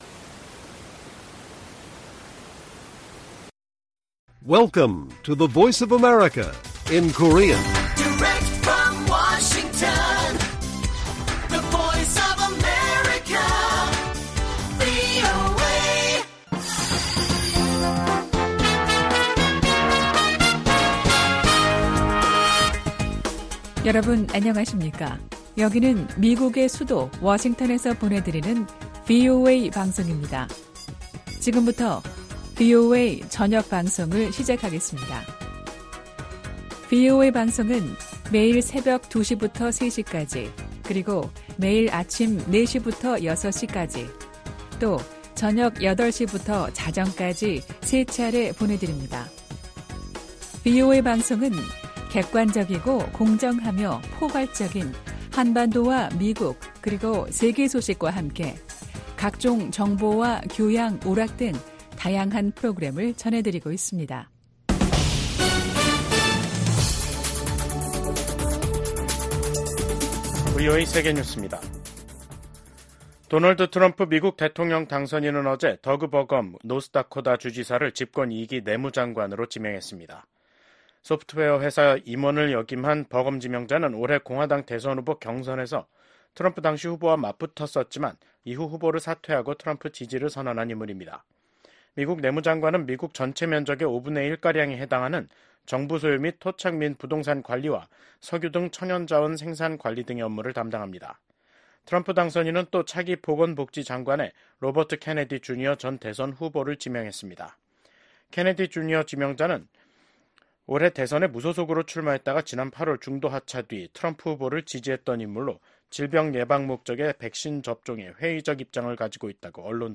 VOA 한국어 간판 뉴스 프로그램 '뉴스 투데이', 2024년 11월 15일 1부 방송입니다. 미국 국무장관이 한국, 일본, 캐나다 외교장관 등과 잇따라 만나 북한군 파병 문제를 논의했습니다. 김정은 북한 국무위원장은 석달 만에 또 자폭 공격형 무인기 성능시험 현지지도에 나서 이 무인기의 본격적인 대량생산을 지시했습니다.